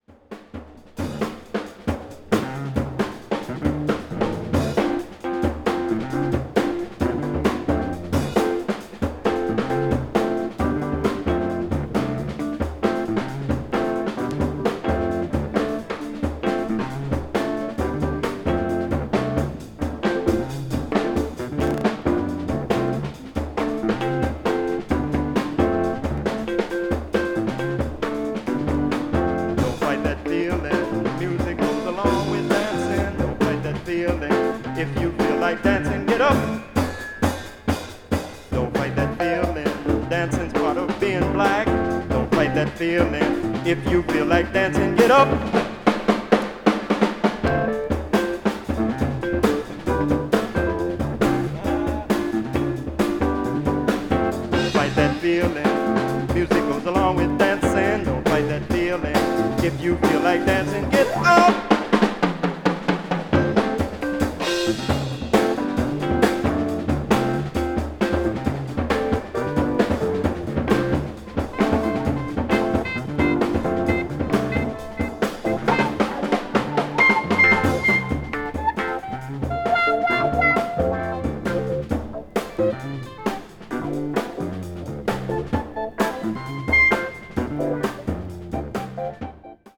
saxophonist
recorded live at the Montreux Jazz Festival in Switzerland